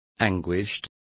Complete with pronunciation of the translation of anguished.